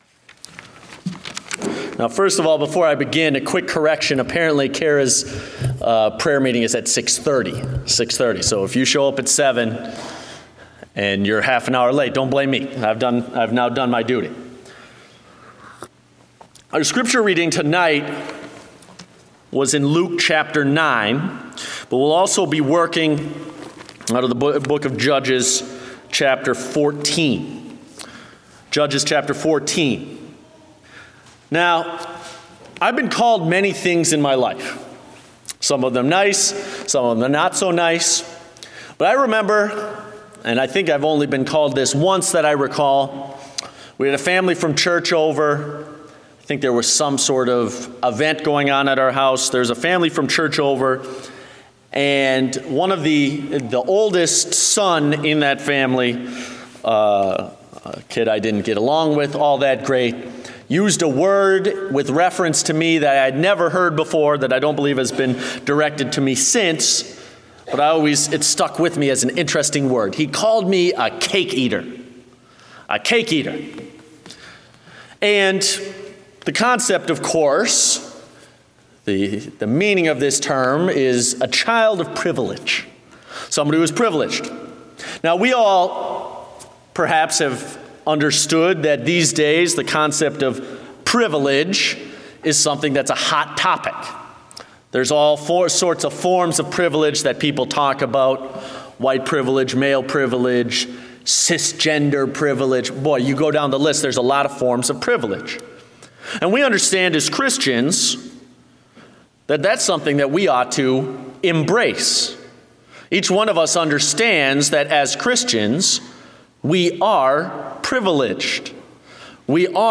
Date: April 9, 2017 (Evening Service)